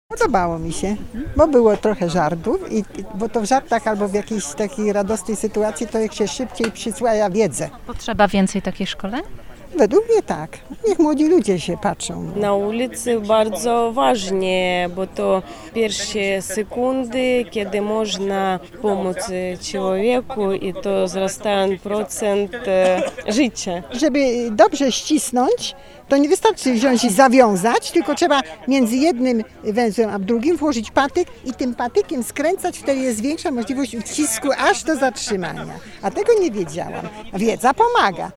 W centrum Wrocławia (pl. Nowy Targ) zorganizowano plenerowe szkolenie z zakresu udzielania pierwszej pomocy medycznej.
sonda-mieszkancy-pierwsza-pomoc.mp3